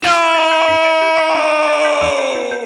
Play, download and share Jeb screams nooo original sound button!!!!
jeb-screams-nooo.mp3